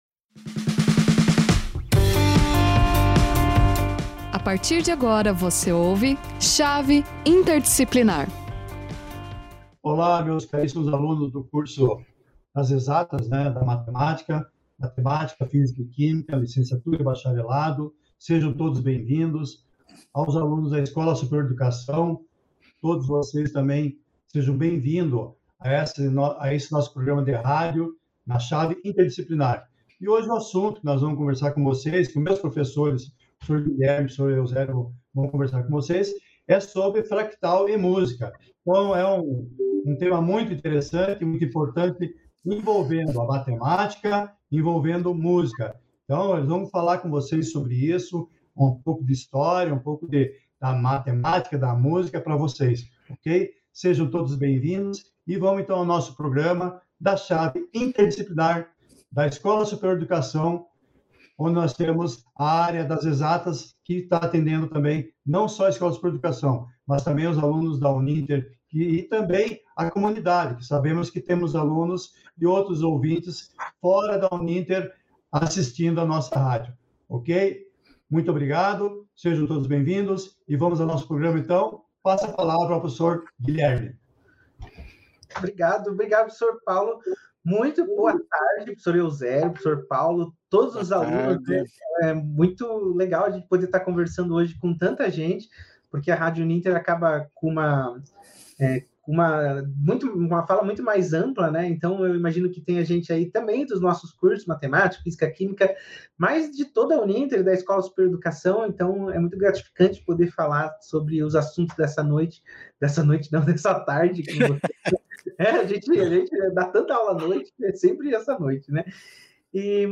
Você faz ideia de como podemos relacionar música e matemática? “Geometria Fractal” é o tema do Chave Interdisciplinar da vez, e para a conversa, contamos com a presença dos professores